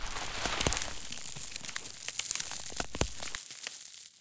bush grows.ogg